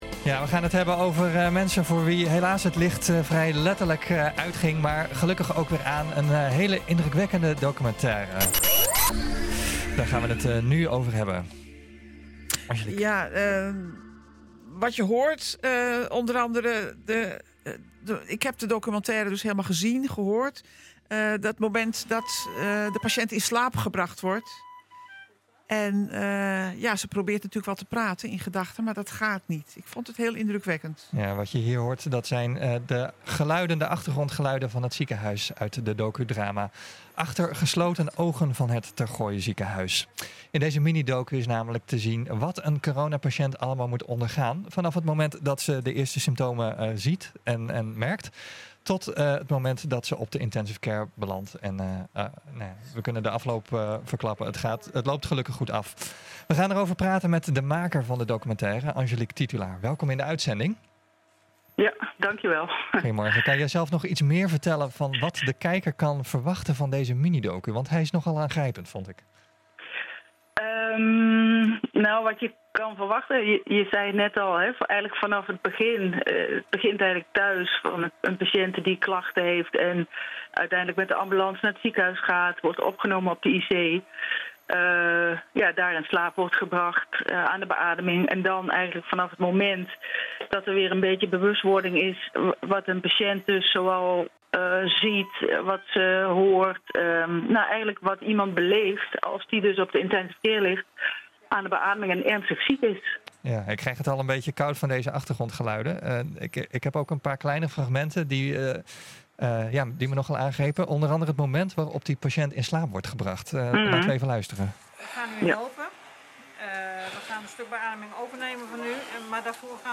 Wat je hier hoort zijn de geluiden de docudrama ‘Achter gesloten ogen’ van het Tergooi Ziekenhuis. In deze minidocu is te zien hoe wat een coronapatient allemaal moet ondergaan, vanaf het moment dat ze symptomen ontvangt tot het belanden op de Intensive Care.